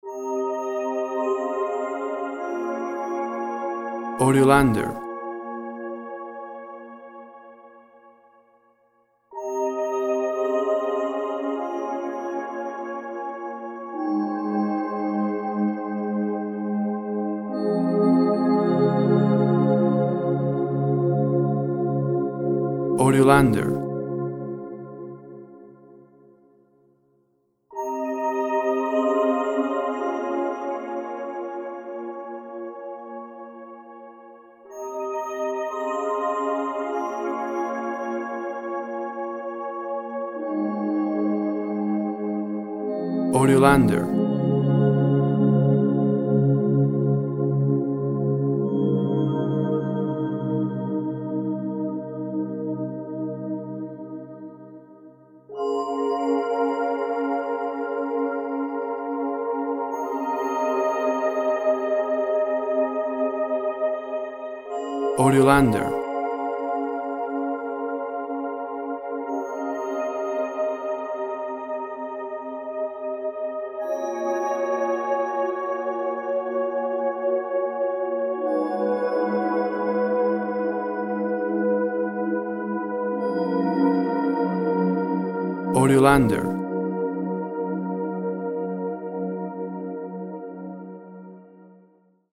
Quiet, drifting synth sounds.
Tempo (BPM) 52